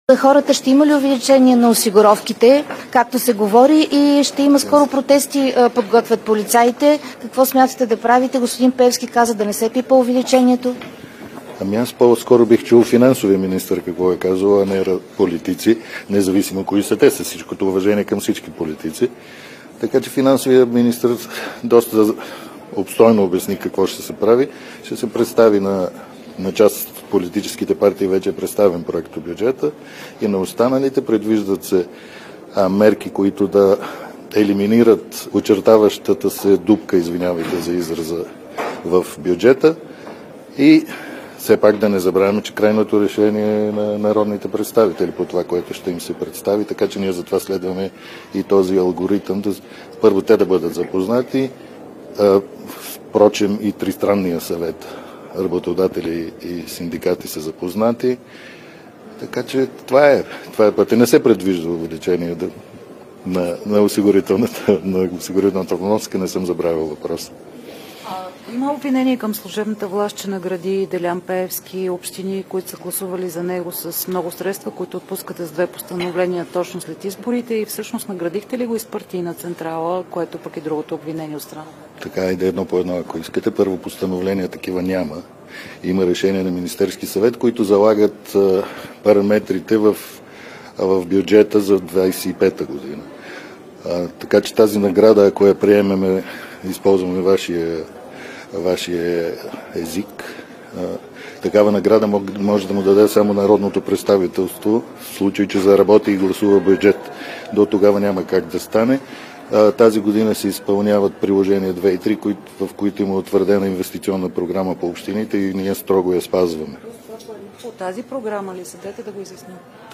Новини на всеки 30 минути
Директно от мястото на събитието